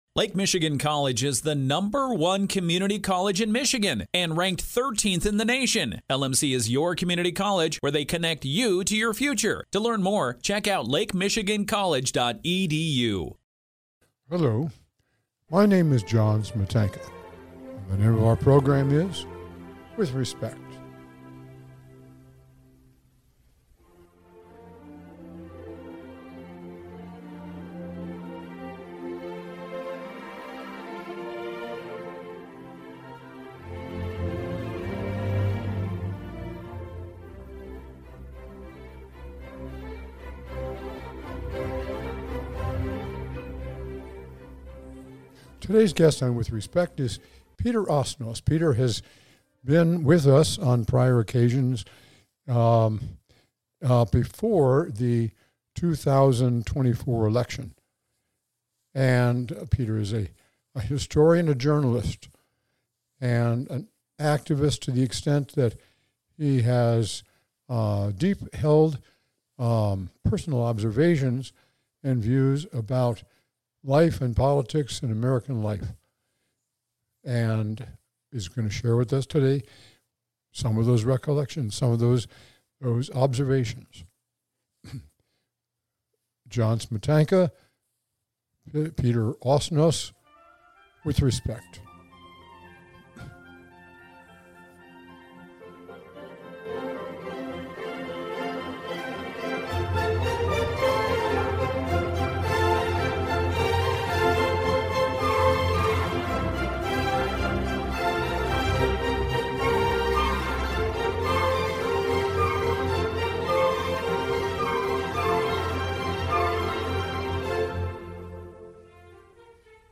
John Smietanka, hosted a radio program in southwestern Michigan along with his career in the law and GOP politics. He interviewed me, for the last time in the summer of 2025, shortly before he died of emphysema (you can hear his labored breathing). His questioning and my answers are worth saving because of how vividly they seem to capture the era of Donald Trump in the context of American history.